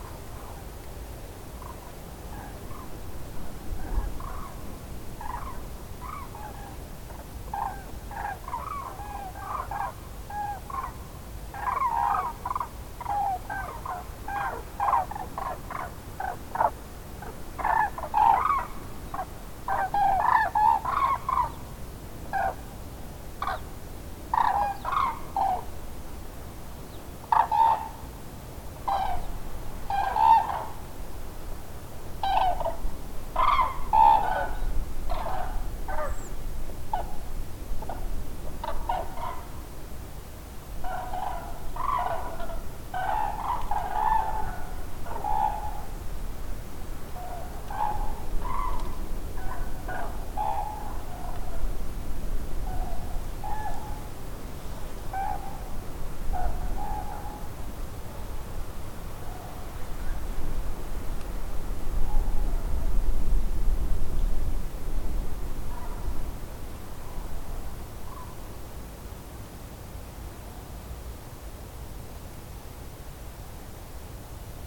Grue cendrée
GRUIDÉS, Grus grus
Désormais il n’est plus rare d’entendre les cris des Grues cendrées en Lorraine en plein cœur de l’hiver, ainsi à la nuit tombée, ce 8 janvier 2024 au-dessus de la côte de Lorry :